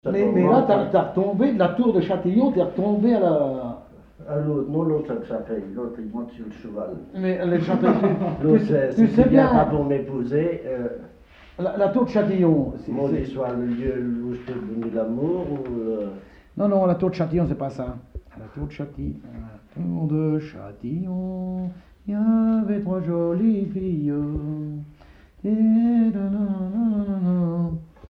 Commentaire
Catégorie Témoignage